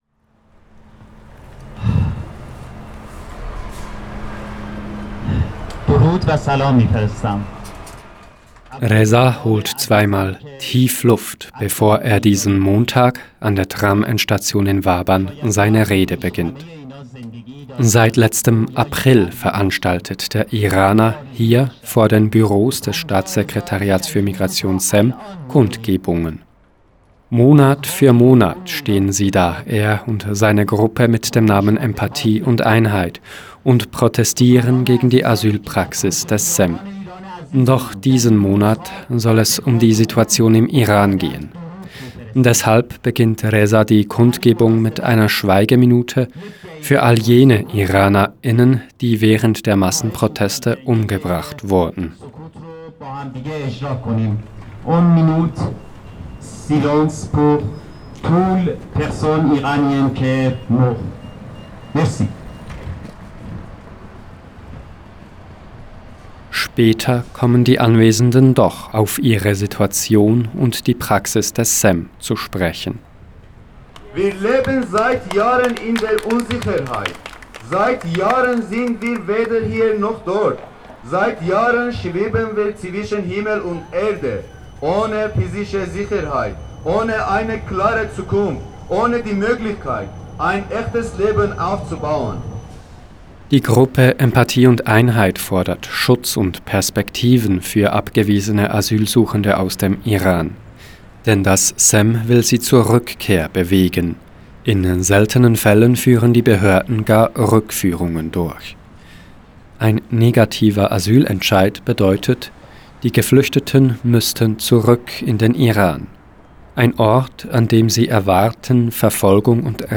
Ein Redner spricht von einer ständigen Situation der Unsicherheit, in der er und seine Mitstreiter*innen sich befinden. Die Gruppe «Empathie und Einheit» fordert Schutz und Perspektiven für abgewiesene Asylsuchende aus dem Iran.